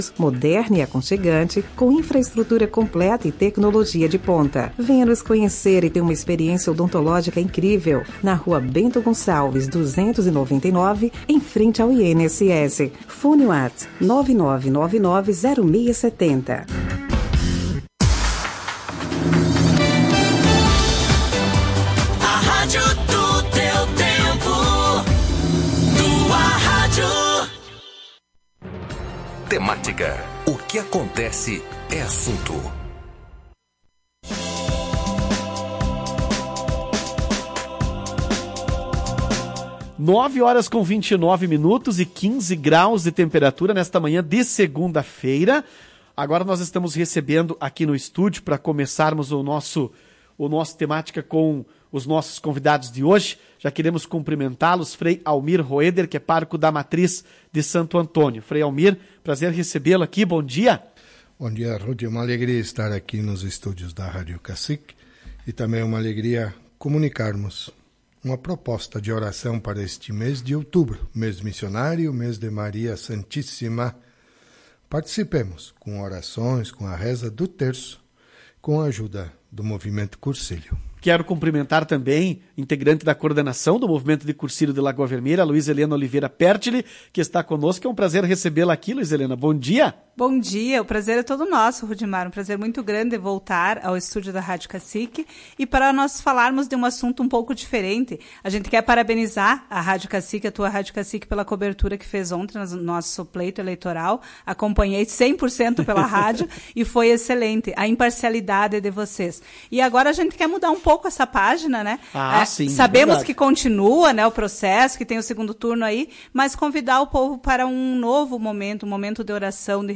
Em entrevista à Tau Rádio Cacique